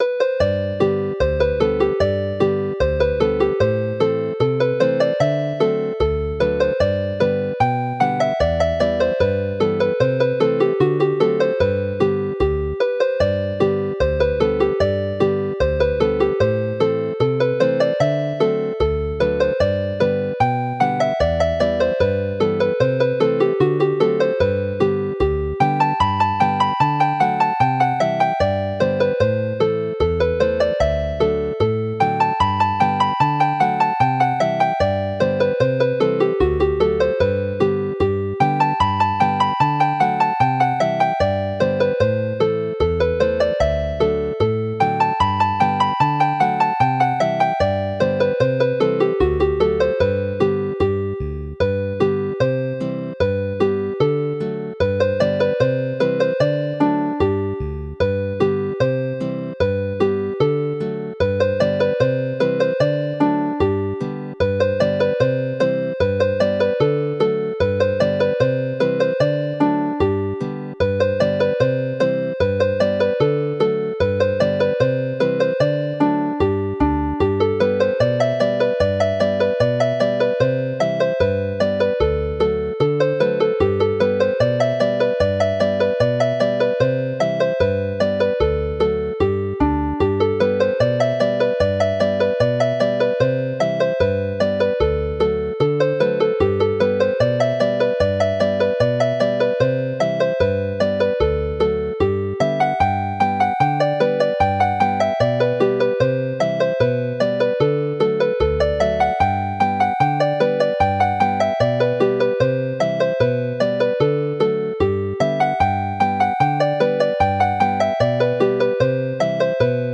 Alawon Cymreig - Set yr Aradr - Welsh folk tunes to play